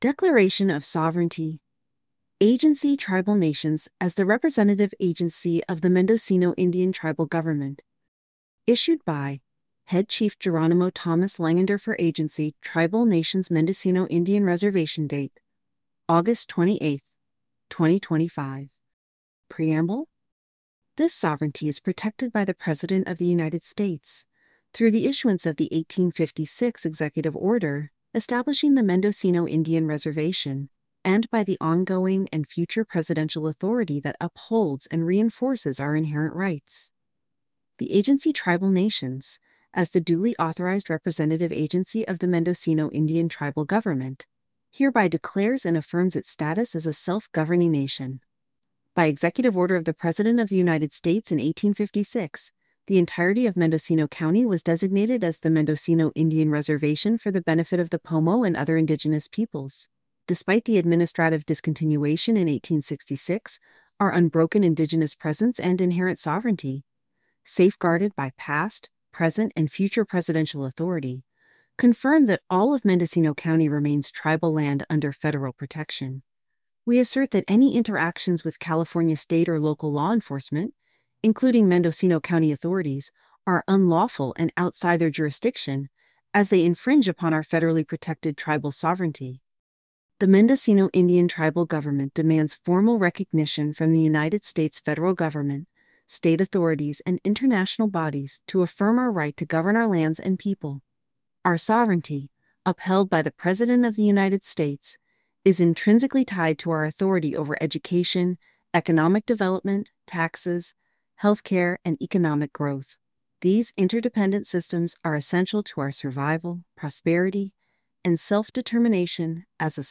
Official Press Release Audio